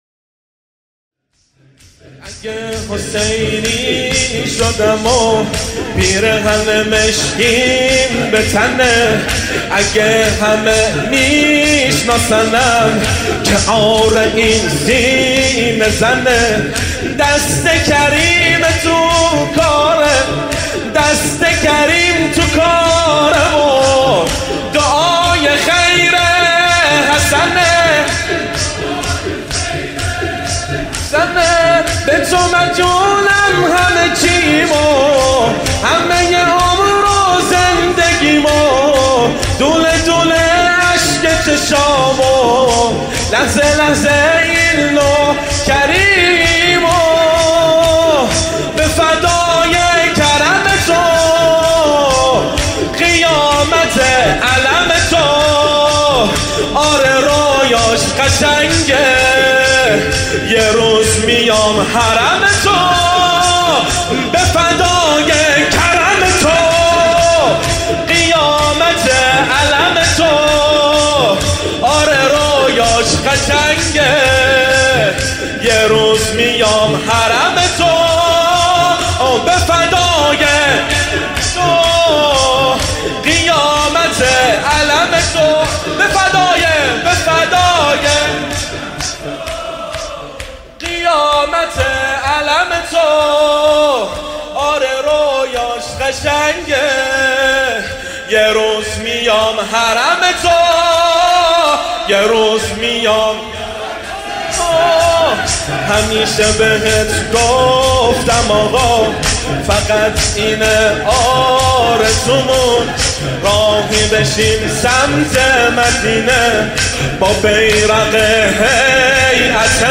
شور
مداحی
شب‌های فاطمیه دوم-1439هجری قمری | هیأت علی اکبر بحرین